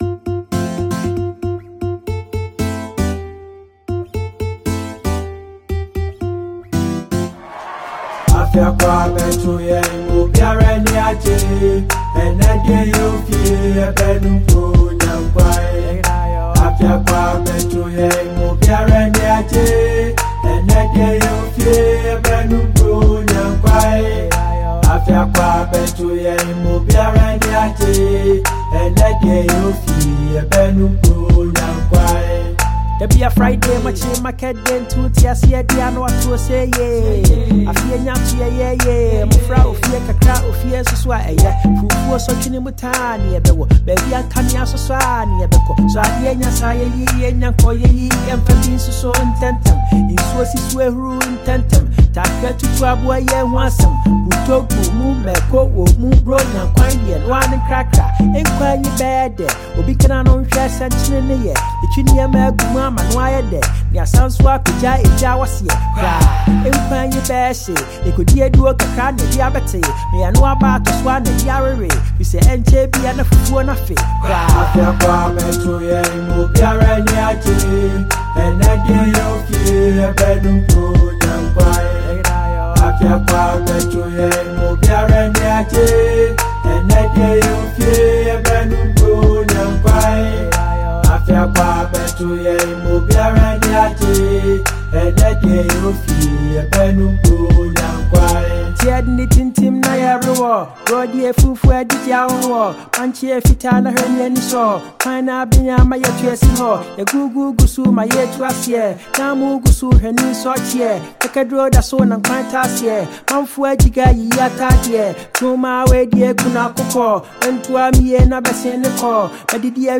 Ghanaian singer and performer